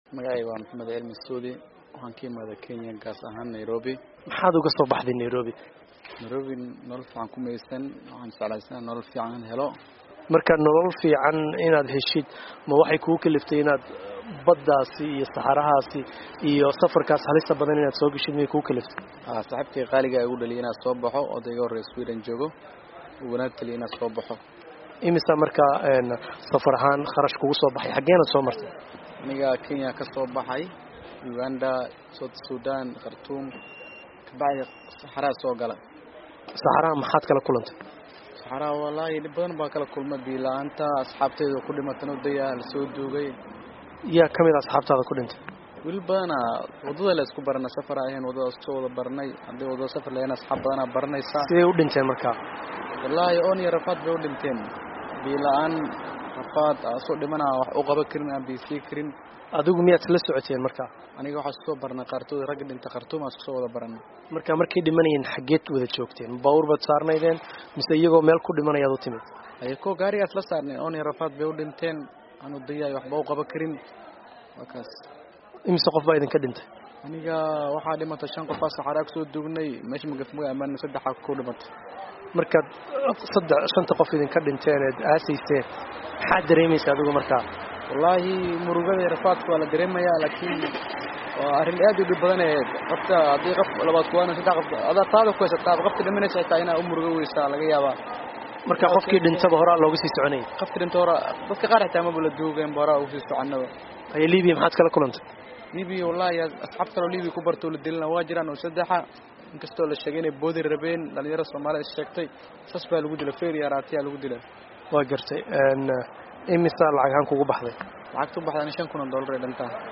Wareysiga